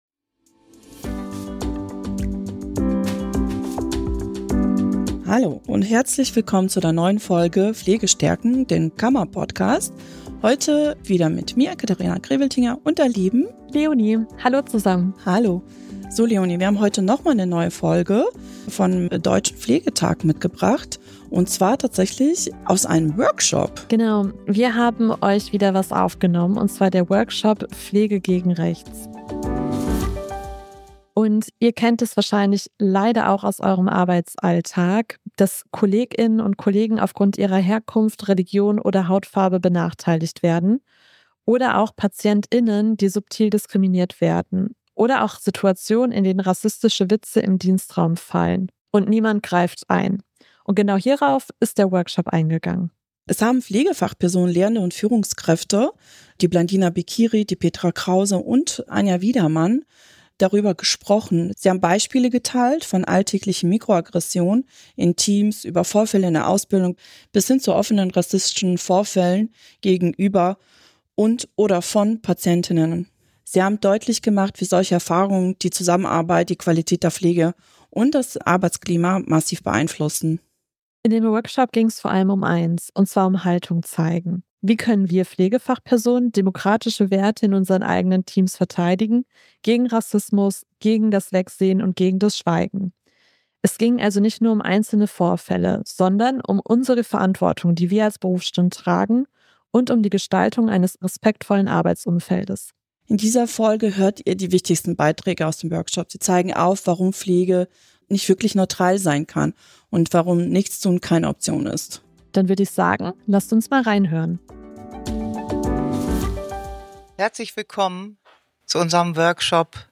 Genau darum geht es in dieser Folge, vom Deutschen Pflegetag 2025 in dem Workshop „Pflege gegen rechts“.